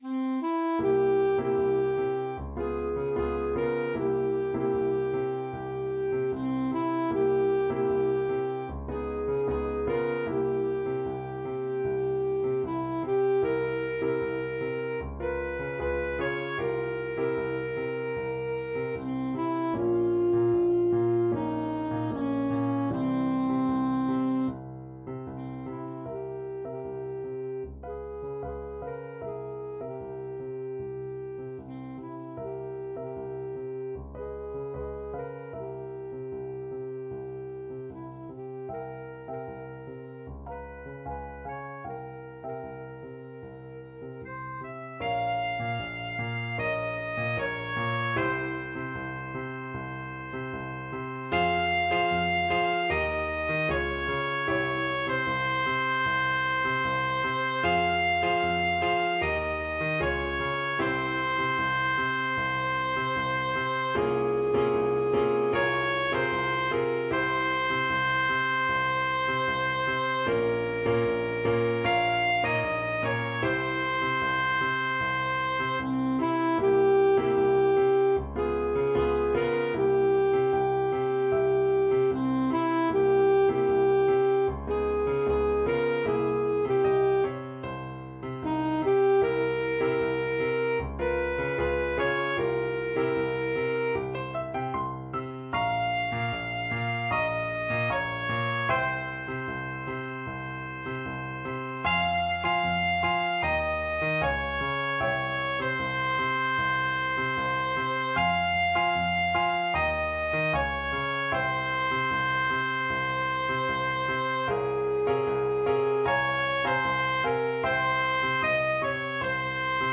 Alto Saxophone
4/4 (View more 4/4 Music)
Jazz (View more Jazz Saxophone Music)